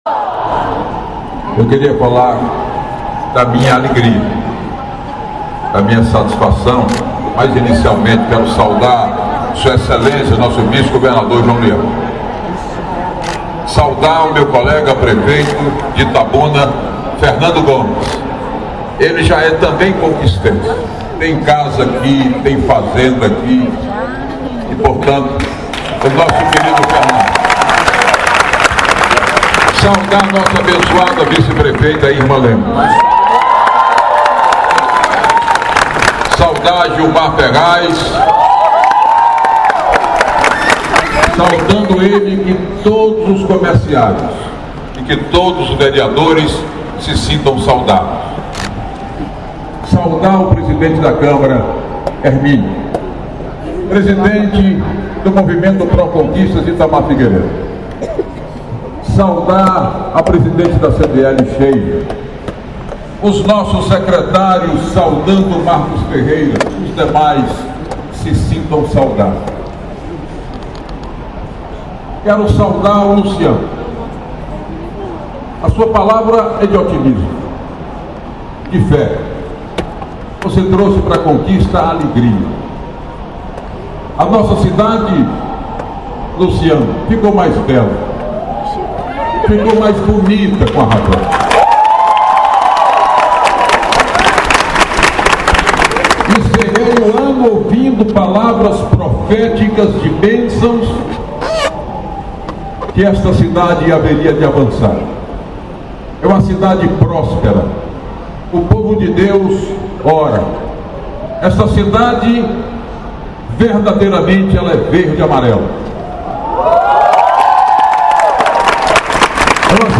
Em seu discurso durante a inauguração da Lojas Havan, o prefeito Herzem Gusmão Pereira falou sobre o empreendimento, mas os destaques ficaram para dois desejos, sendo que um será realizado logo em breve.